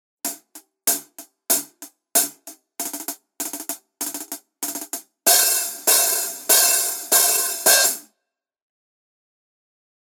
Это обеспечивает невероятно широкий динамический диапазон, землистый теплый звук, четкую атаку и отличную читаемость в миксе.
Masterwork 13 Custom Pointer Hats sample
CustomPointer-Hihat-13.mp3